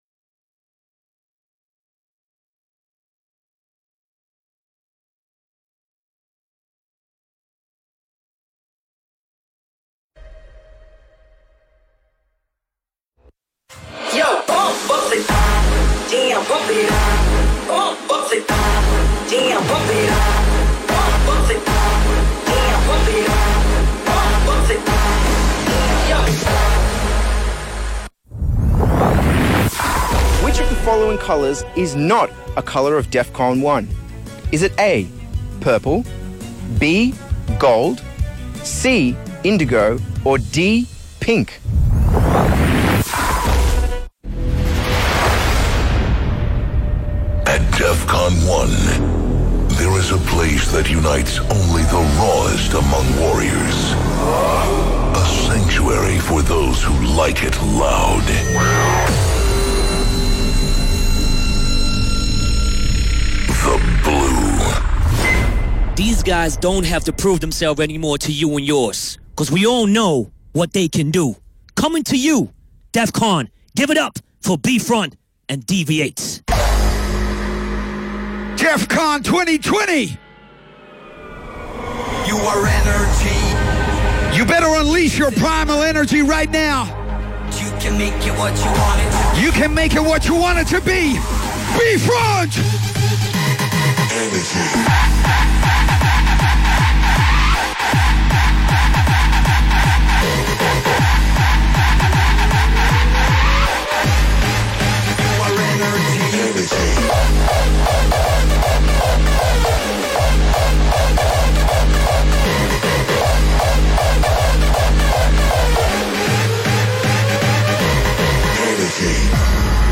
Live Set/DJ mix